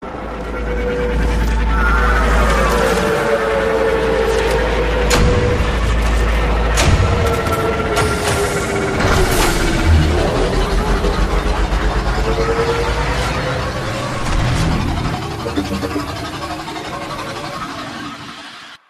Шум посадки ракеты